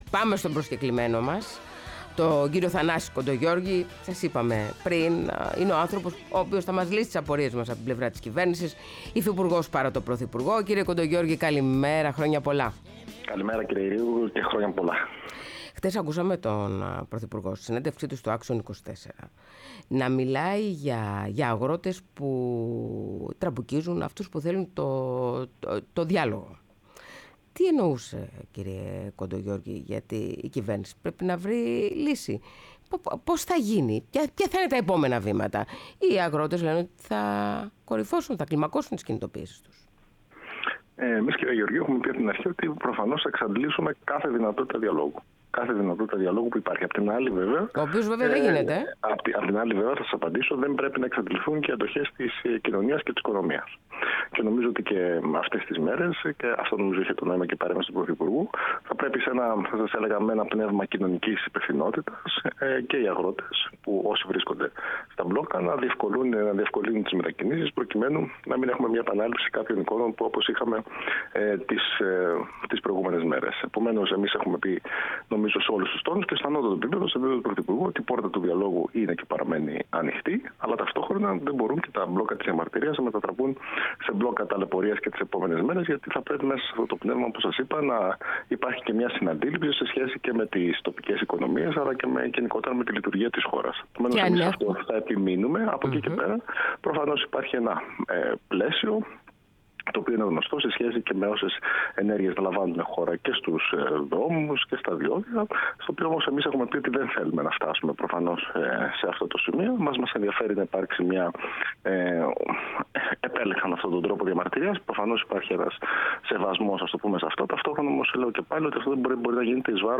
Ο Θανάσης Κοντογεώργης, Υφυπουργός παρά τω Πρωθυπουργό, μίλησε στην εκπομπή “Πρωινές Διαδρομές”